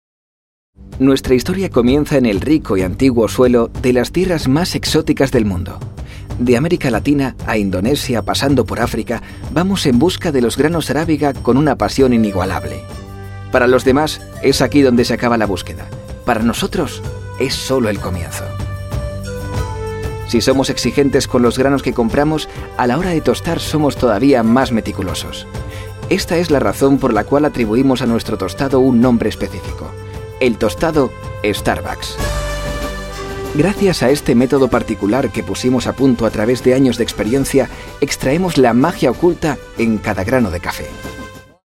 Sprecher spanisch für Hörfunk und Werbung
Sprechprobe: Sonstiges (Muttersprache):